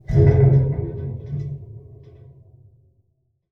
metal.wav